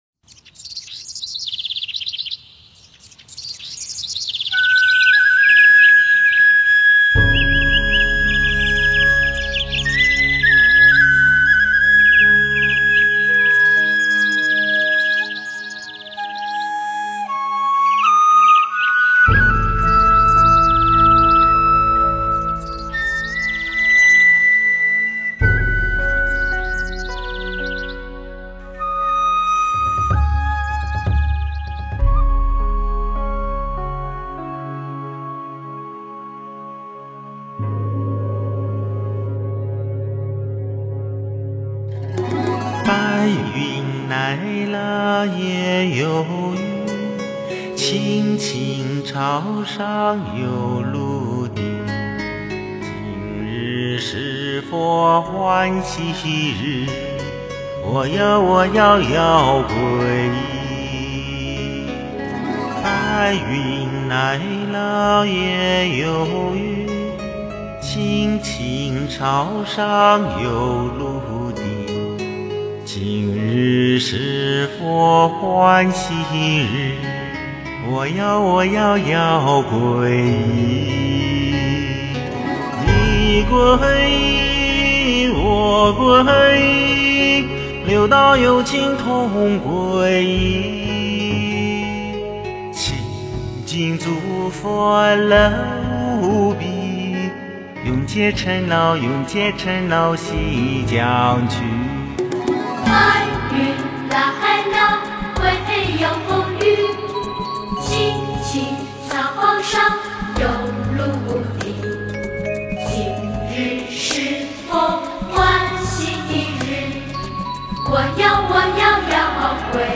诵经
佛音 诵经 佛教音乐 返回列表 上一篇： 观世音菩萨大悲心及大悲手印双运心咒 下一篇： 南无阿弥陀佛 相关文章 佛母准提神咒--黑鸭子 佛母准提神咒--黑鸭子...